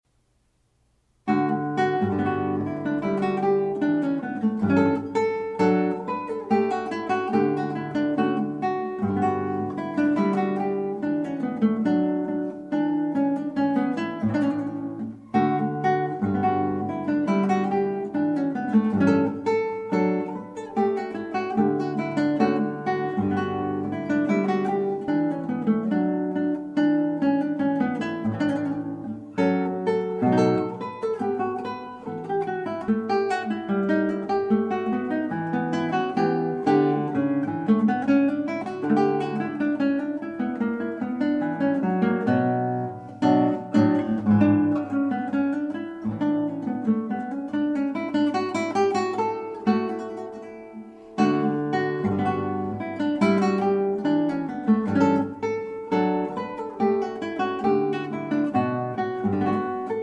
guitar
transcribed for guitar